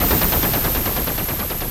RI_RhythNoise_140-04.wav